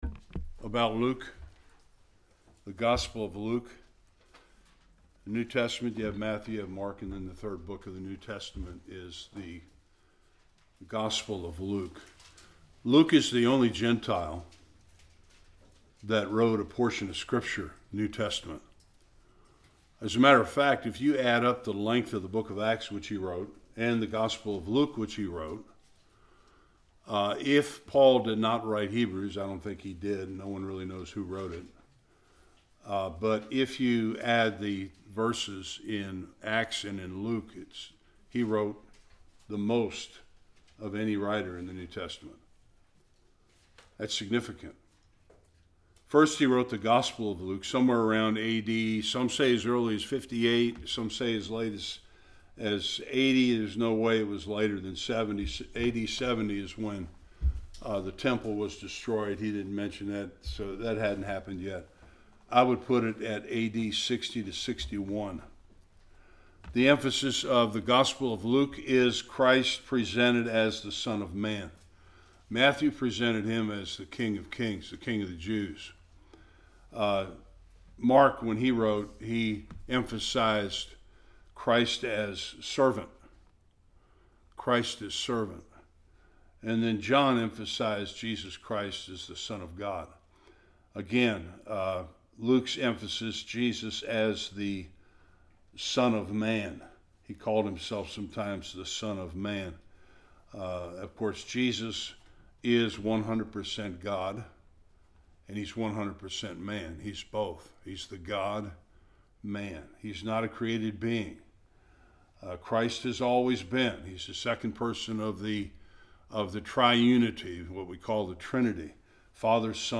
1-25 Service Type: Bible Study The introduction to the Gospel of Luke.